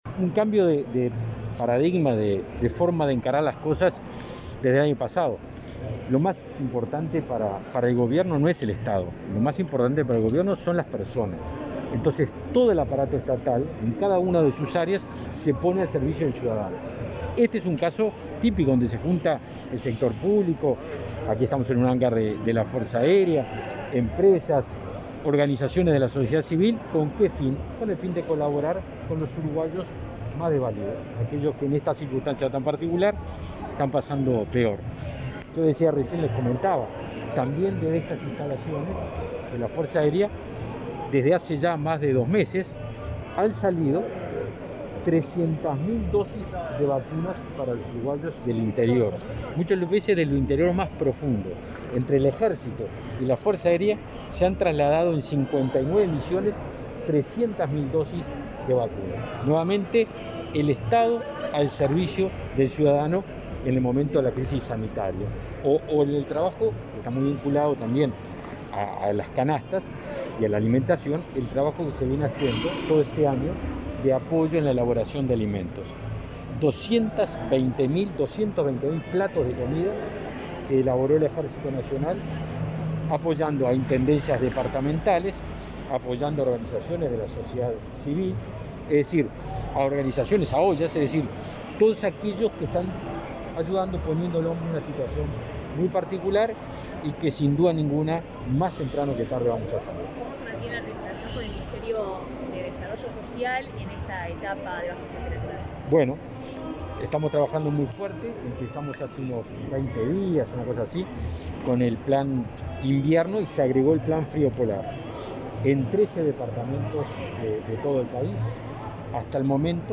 Declaraciones del ministro de Defensa, Javier García